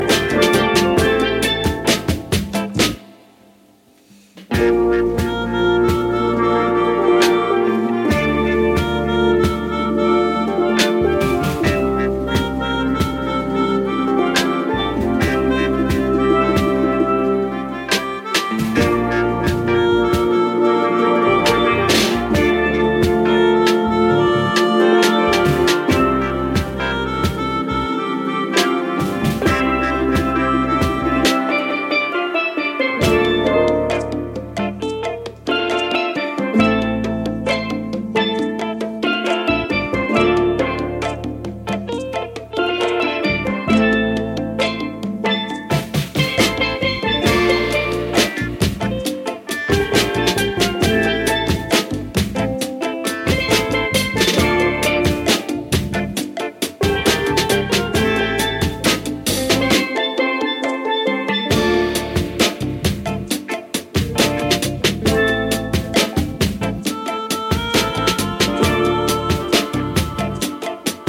funk 7"
an eerie cover